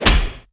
空手击中zth700516.wav
通用动作/01人物/03武术动作类/空手击中zth700516.wav
• 声道 單聲道 (1ch)